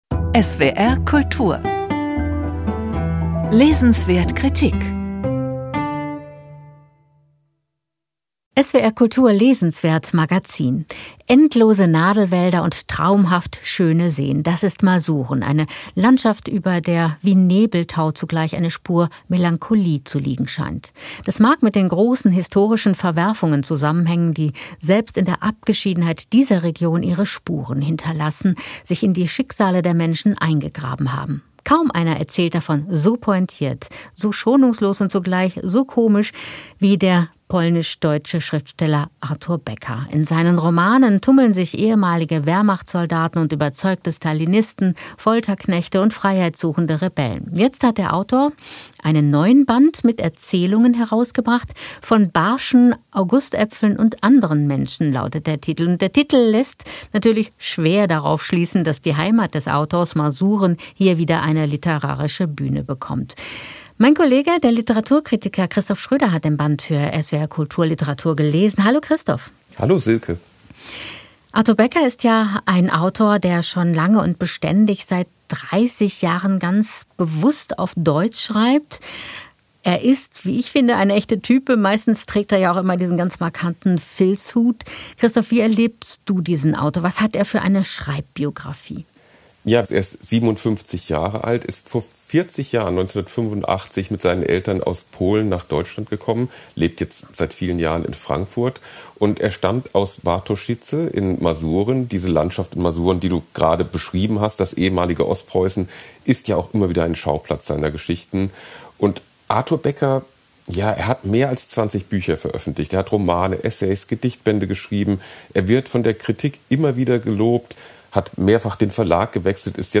Mit einer Sammlung unterschiedlicher Geschichten erweist sich Autor Artur Becker erneut als großartiger Erzähler. Gespräch